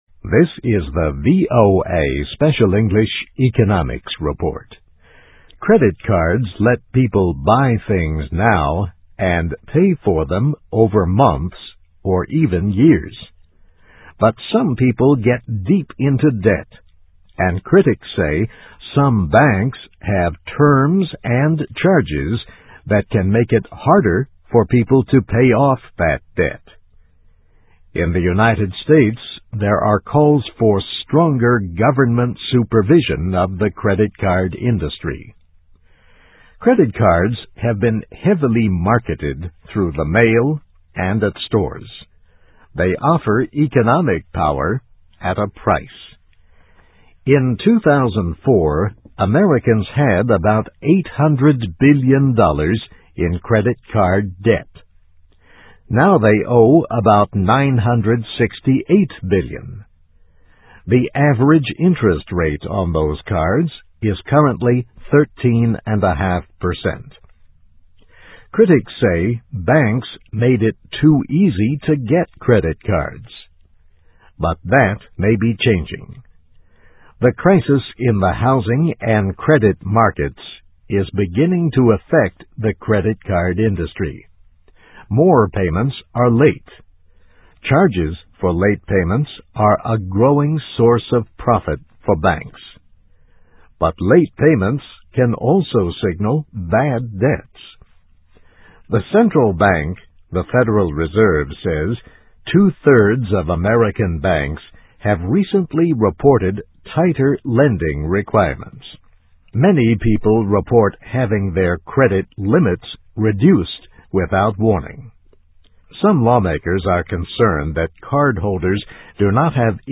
Voice of America Special English